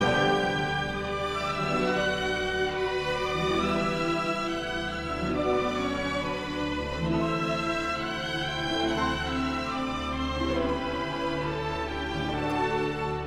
Вот фрагмент из увертюры Калинникова: